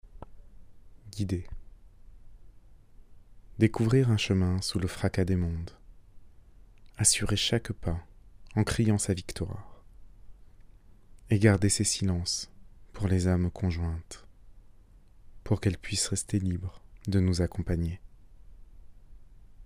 Il contient 23 poèmes lus par votre serviteur en mp3.